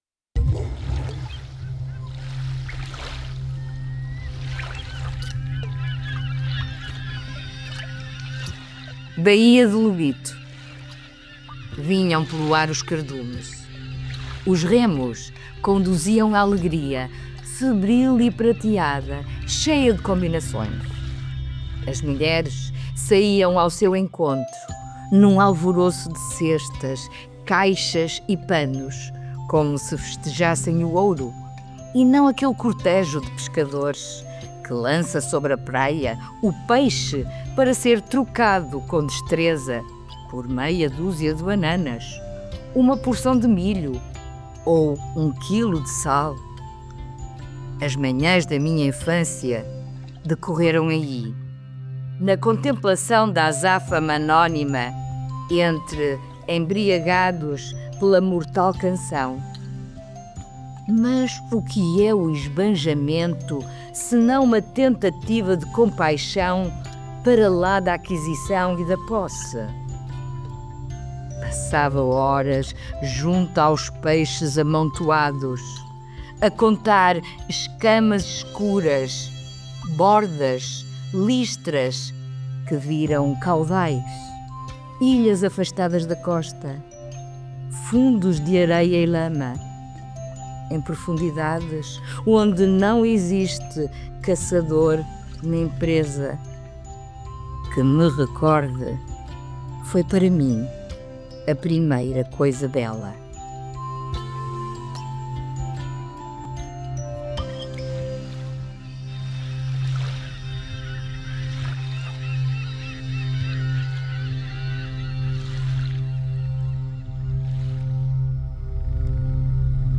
Música: “Marinheiro”, Músicas tradicionais, licença Cantar Mais